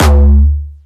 Jumpstyle Kick Solo